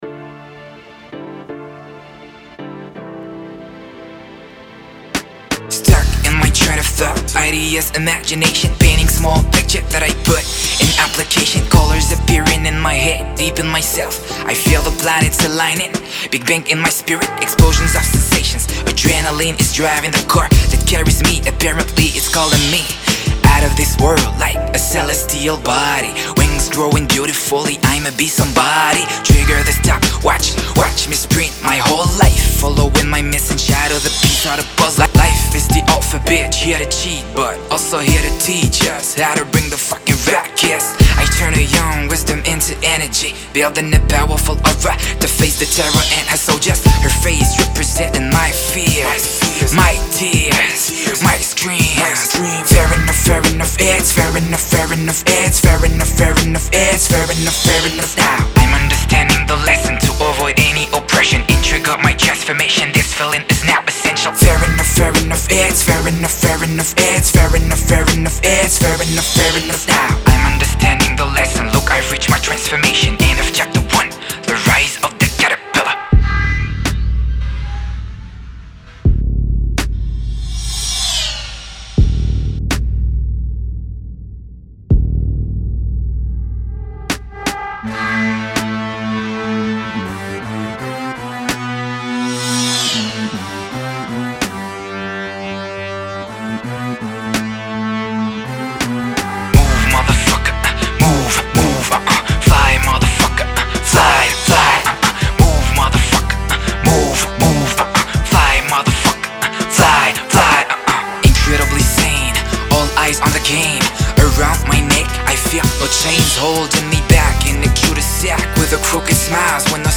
(RAP)